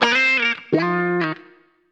WAV guitarlicks